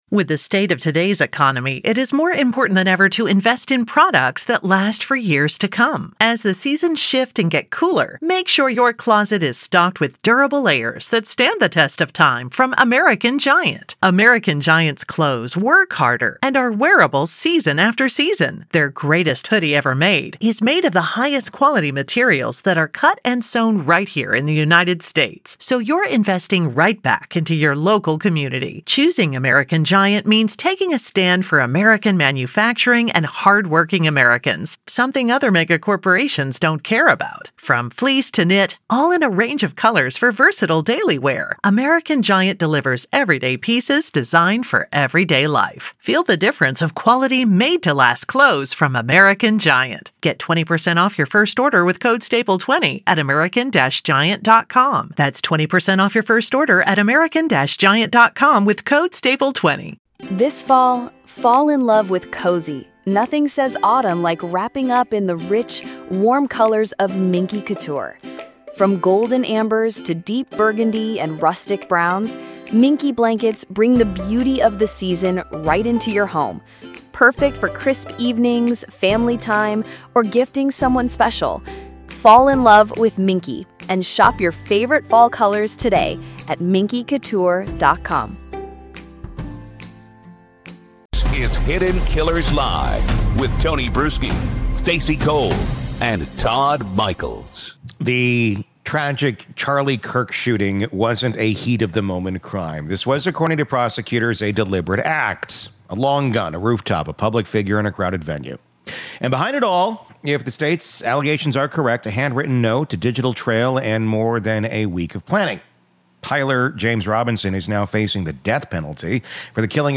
This interview dives deep into the dark corners of obsession, warning signs, and the narratives people build around violence.